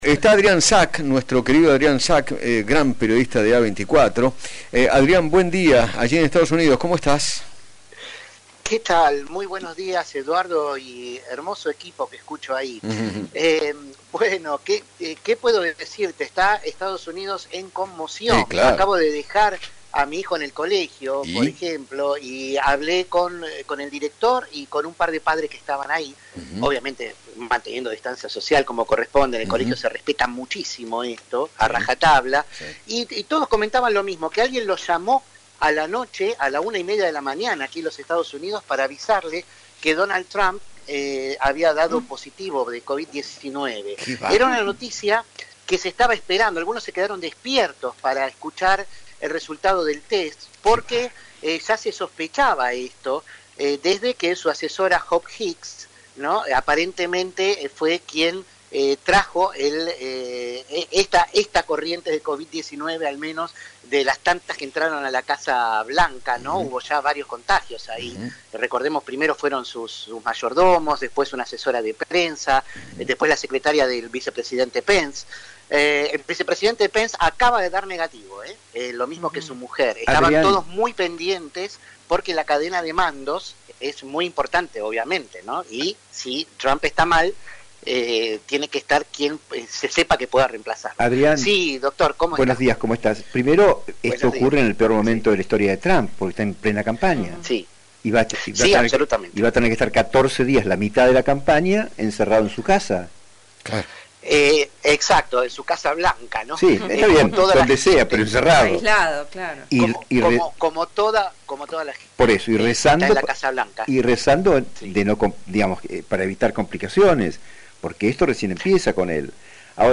periodista en Estados Unidos, dialogó con Eduardo Feinmann acerca del resultado de los hisopados del presidente norteamericano y su esposa, Melania Trump, que mantiene en vilo al mundo.